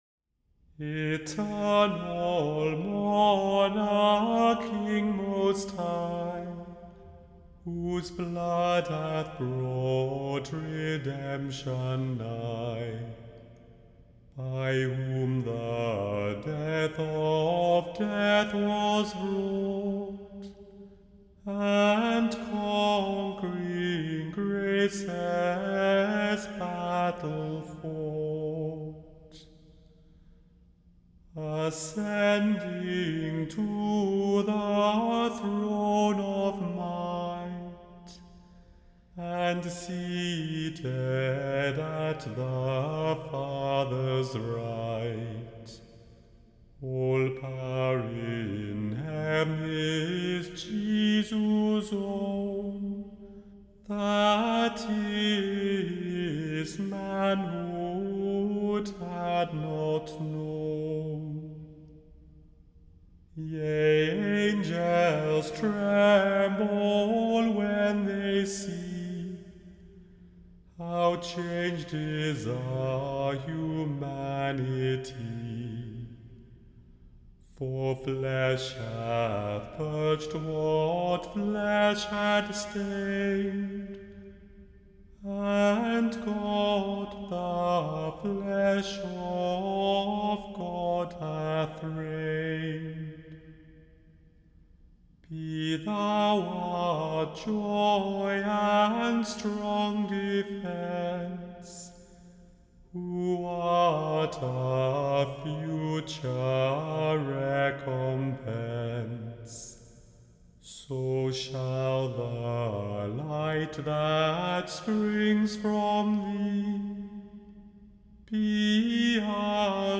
The Chant Project – Chant for Today (July 3) – Eternal monarch – Immanuel Lutheran Church, New York City